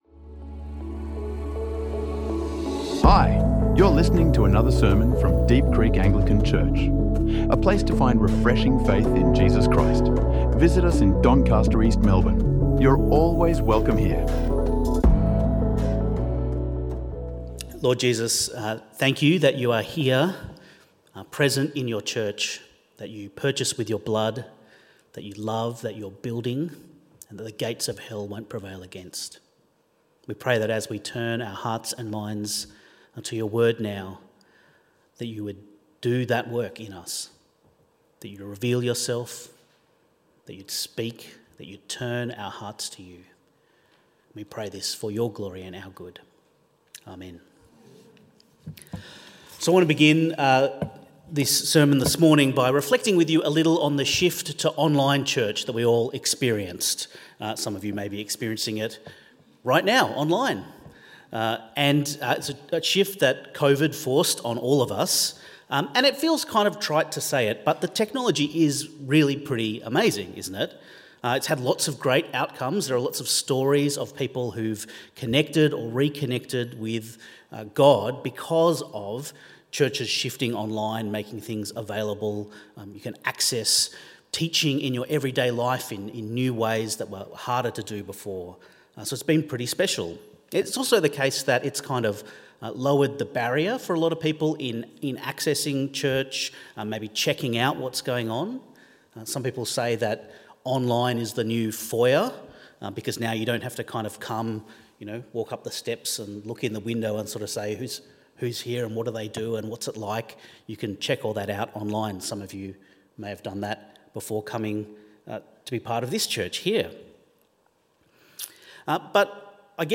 In Our Unified Diversity | Sermons | Deep Creek Anglican Church